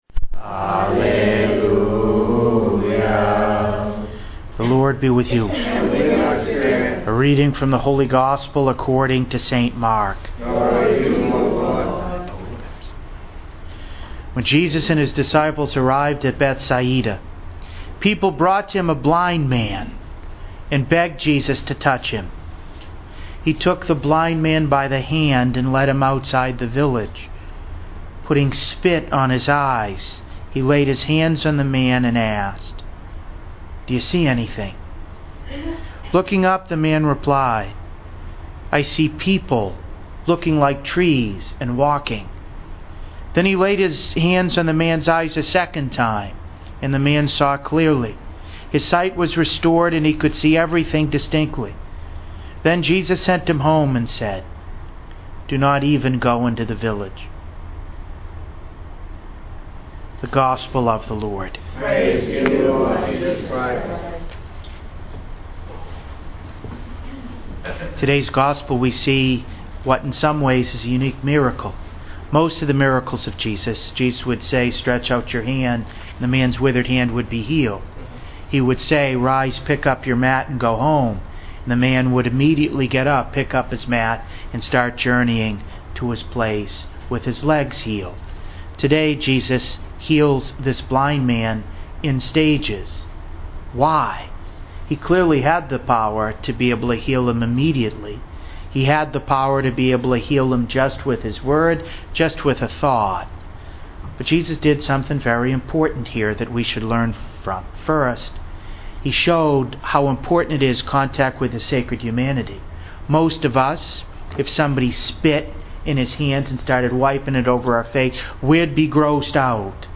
To listen to an audio recording of today’s homily, please click below:
The following points were attempted in the homily: